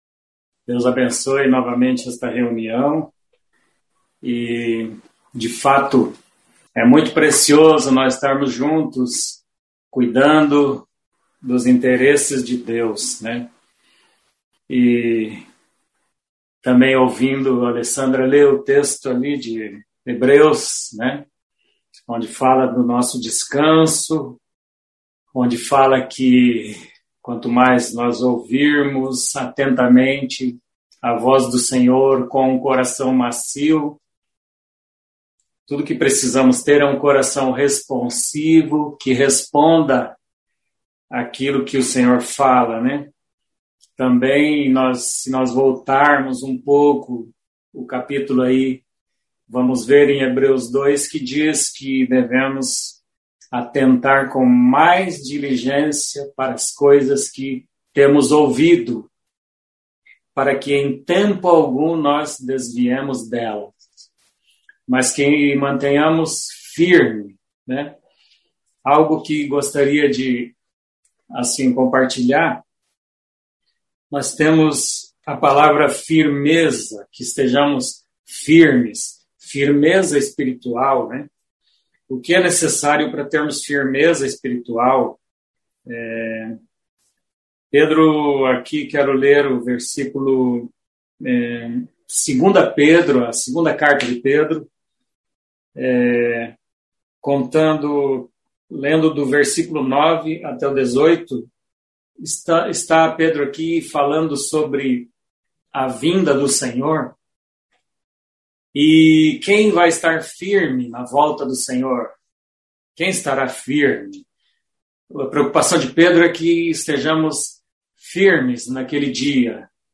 Mensagem
na reunião de mulheres em Curitiba através do aplicativo